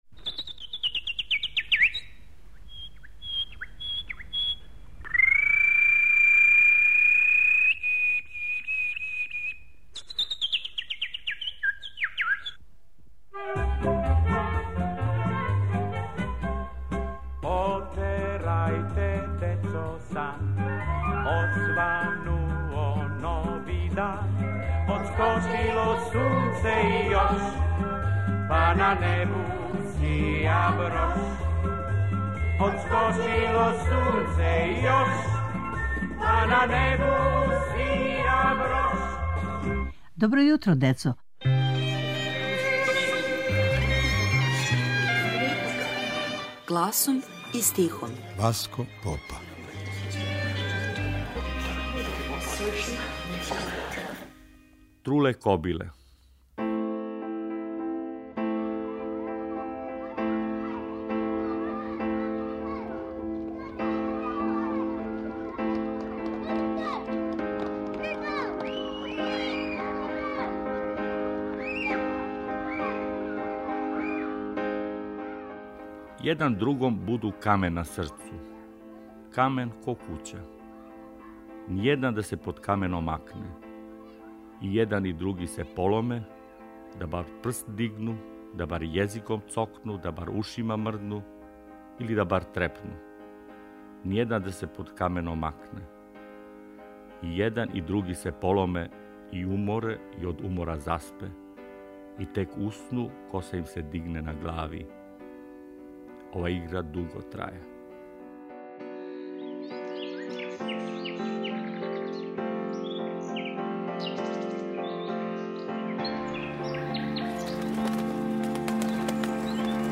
У серијалу "Гласом и стихом" - из старе фиоке архиве Радио Београда, гласом и стихом, јављају се чувени песници за децу. Ово је јединствена прилика за слушаоце јер су у питању аутентични звучни записи које само Радио Београд чува у својој архиви. Ове недеље - Васко Попа.